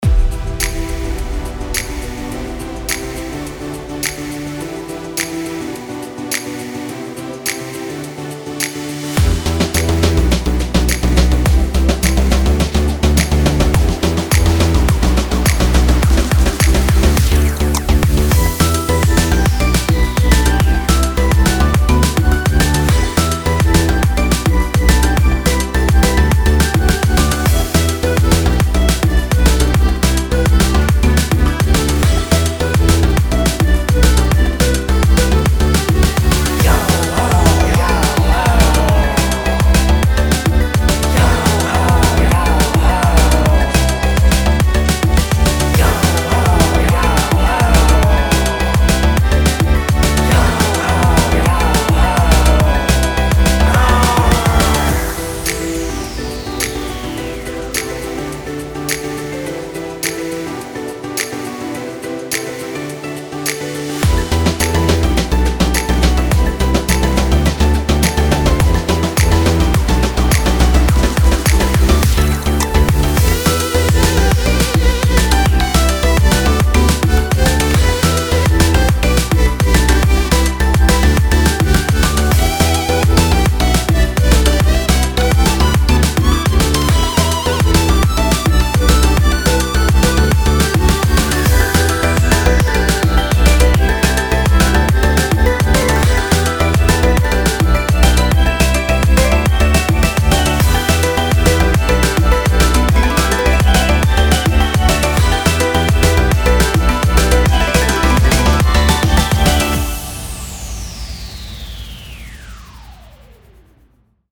Lead Guitar
Drum N Bass